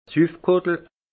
Ville Prononciation 68 Munster